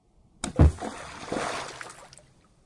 溅射2
描述：Splash.wav，去掉了"thud"部分
标签： 池塘 飞溅
声道立体声